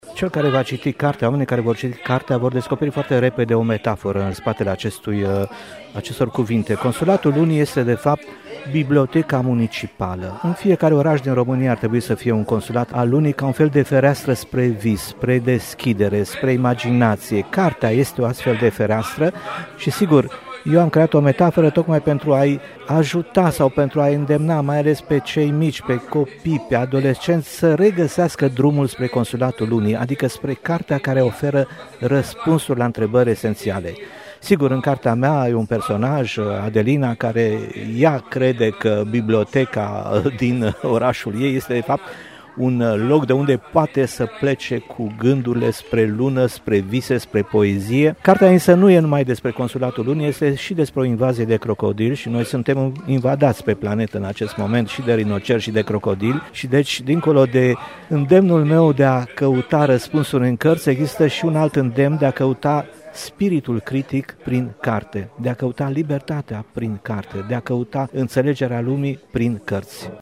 Matei Vișniec a adus „Consulatul Lunii” la Iași – lansare de carte în cadrul FITPTI
„Poezia și inocența vor salva lumea”, spune Matei Vișniec, care a adus la Iași „Consulatul Lunii”, o carte ce deschide copiilor și adulților deopotrivă o fereastră spre vis și imaginație. Lansarea volumului a avut loc la Librăria Cărturești Palas, în cadrul Festivalului Internațional pentru Publicul Tânăr.